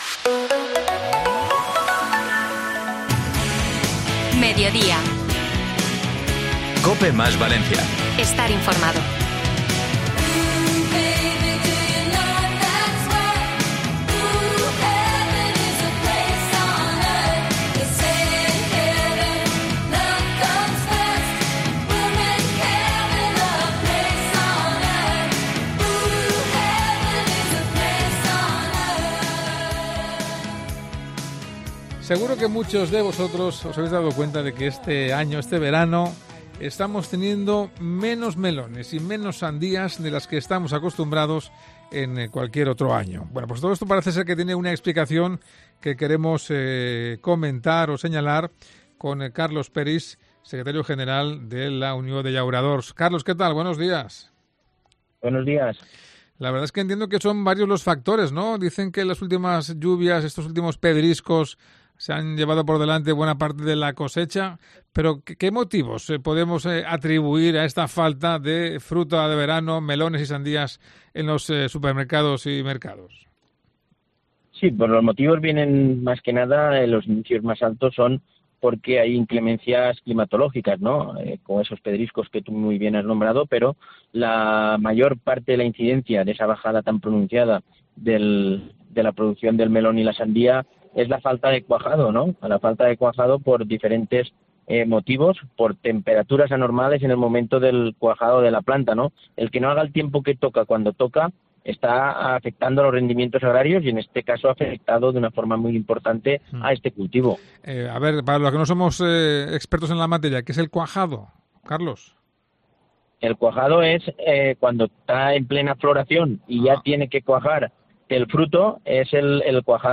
Entrevista | Cómo seleccionar un buen melón